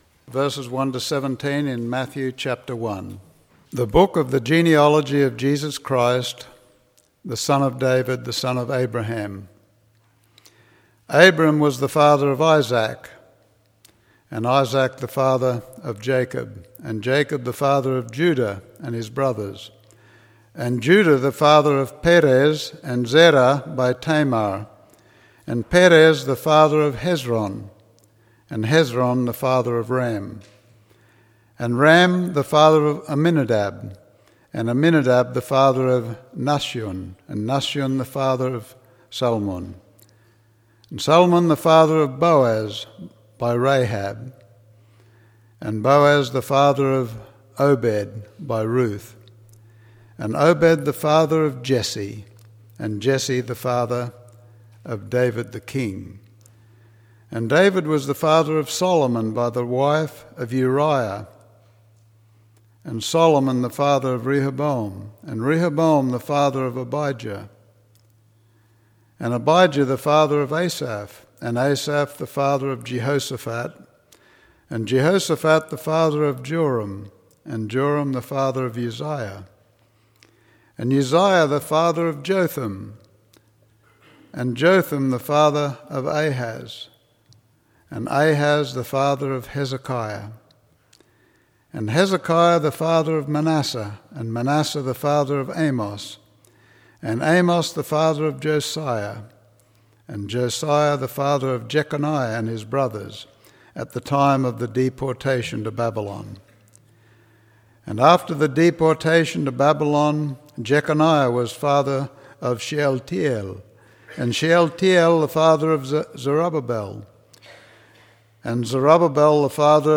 Advent Conspiracy Passage: Matthew 1:1-17 Matthew 1:1-17. Sermon